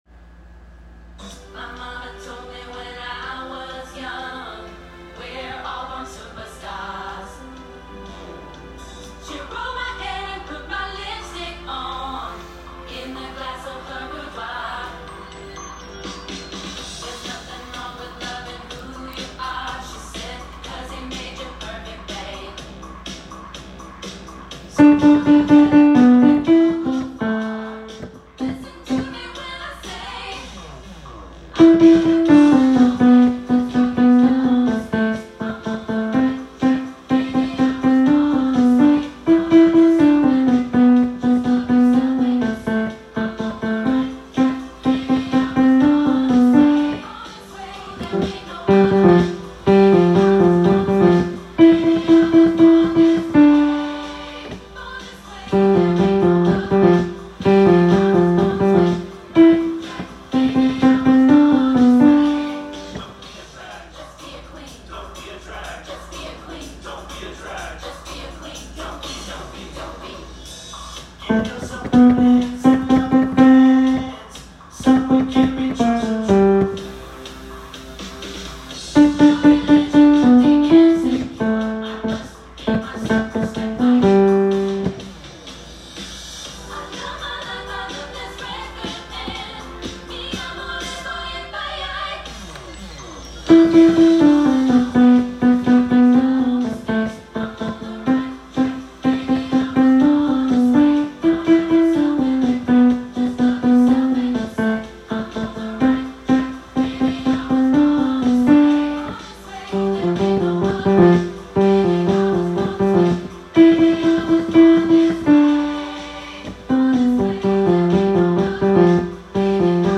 Tenor Part Track